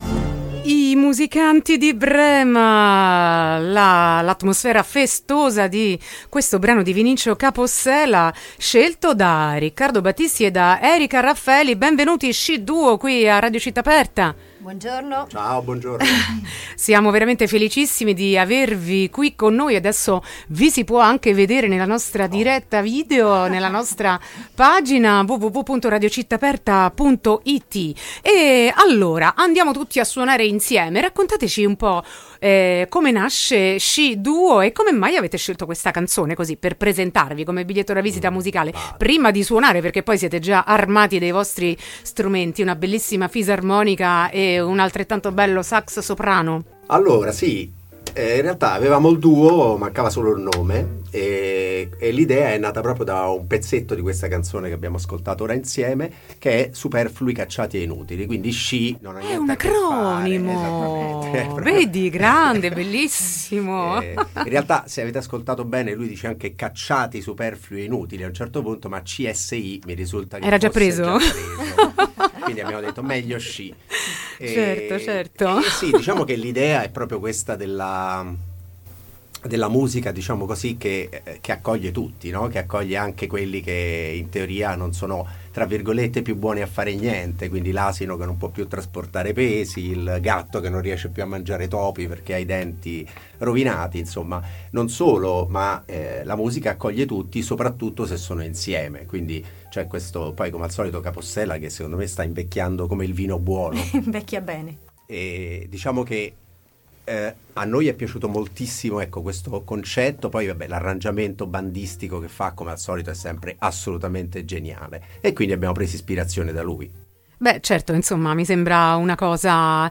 Ospiti in studio
sax soprano e sax tenore
fisarmonica e voce (ma solo a volte…)
una milonga un po’ matrioska, uno swing yiddish
un tango della lentezza
due brani live in studio